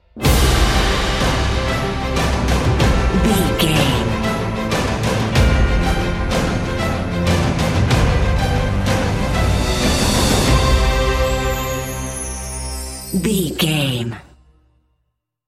Ionian/Major
powerful
brass
cello
drums
piano
strings
trumpet